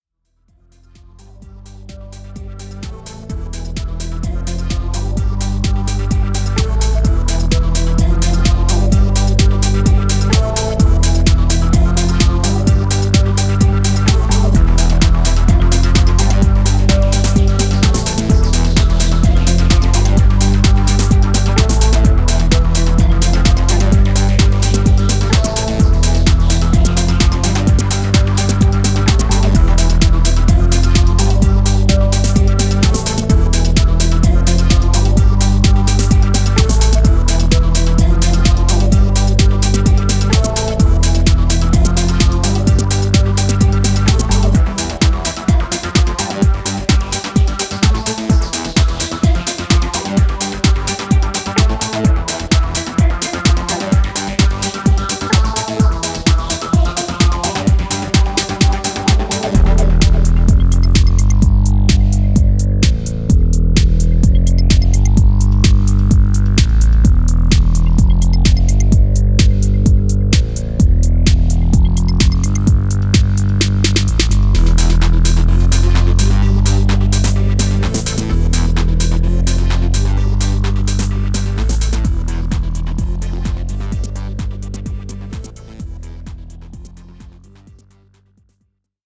ニュースクールな地下テック・ハウスを披露しています。